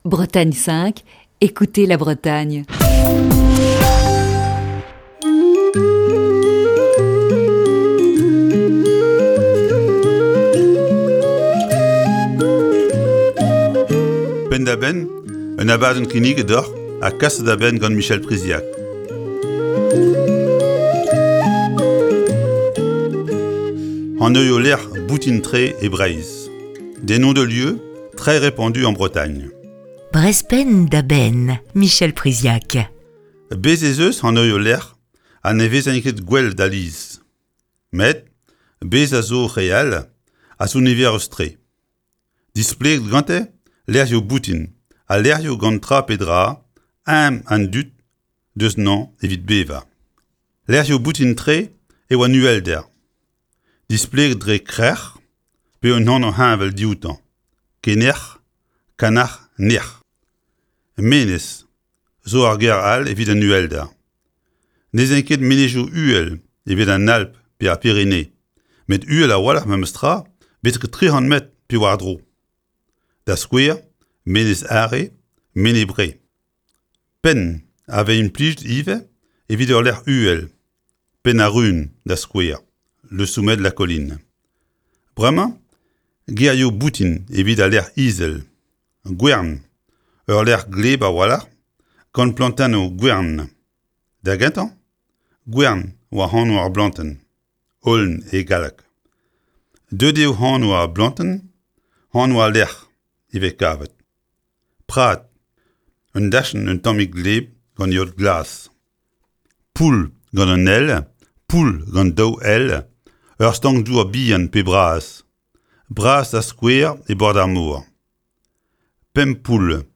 Chronique du 2 juillet 2020.